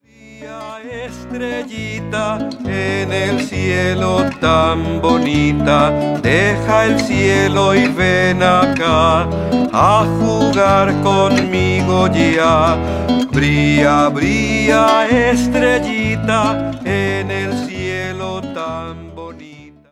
Una relajante versión del clásico inglés